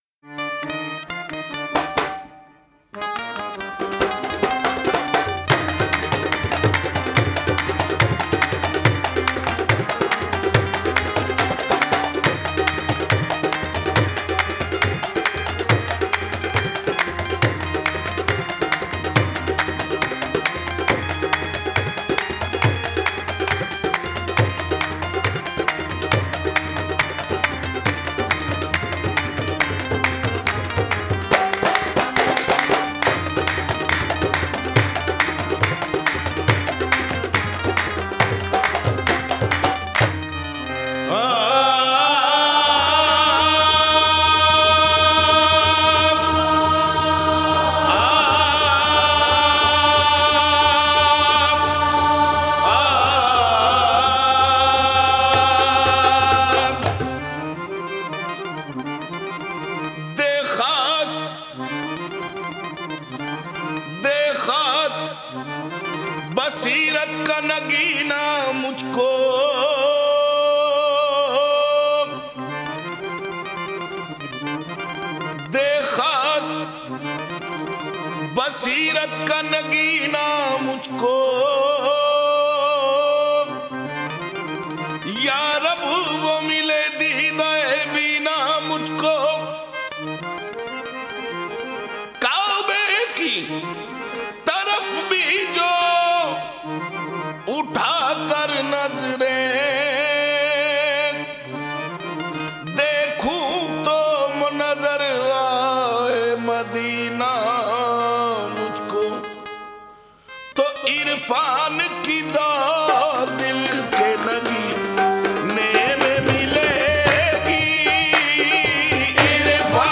Urdu Qawwali
inheriting his style as well as strong powerful voice.
Qawwali